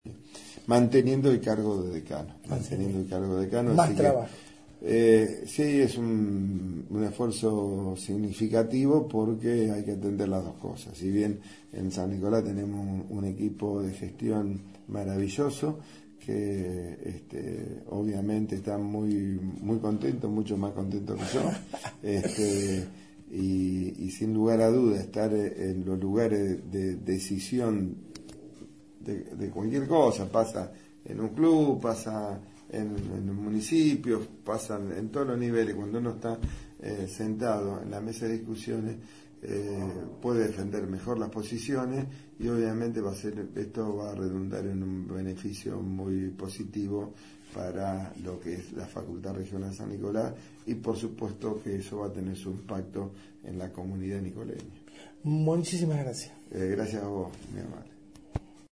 Audio: Charla con el Ing.